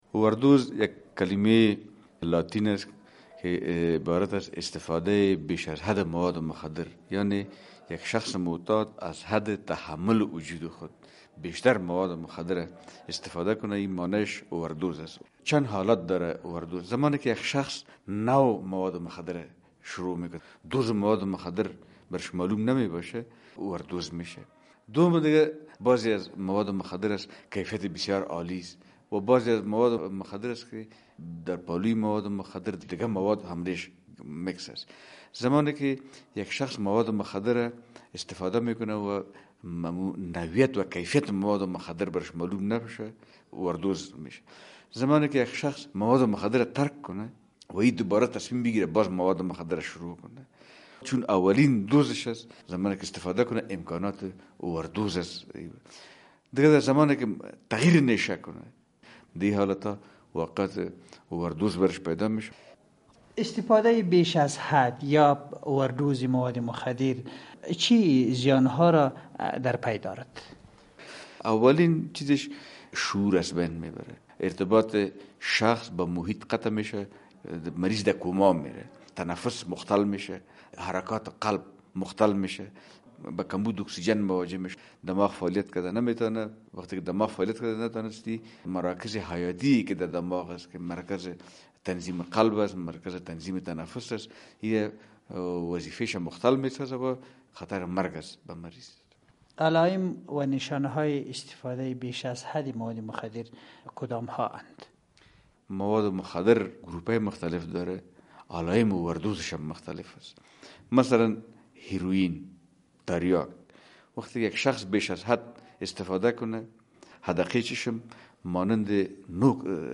خبرنگار رادیو آزادی در این رابطه با داکتر